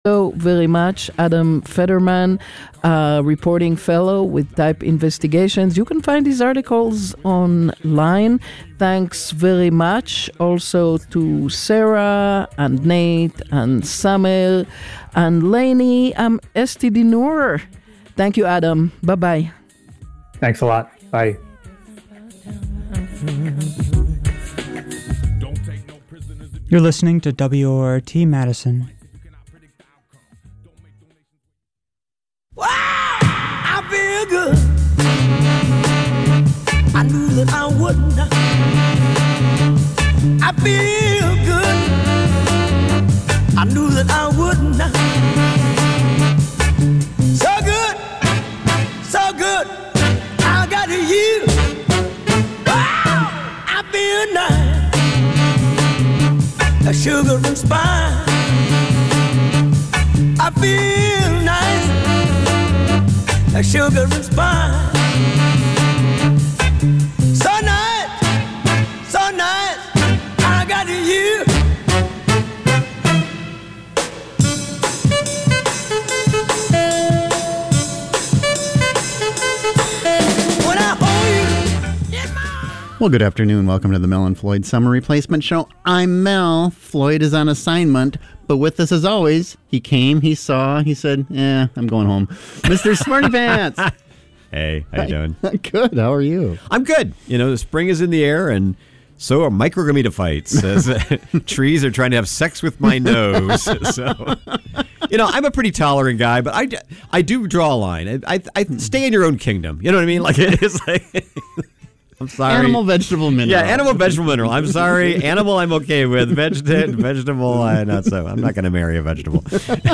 Two wacky funny guys broadcast a hilarious blend of political commentary, weird news, and stand up comedy.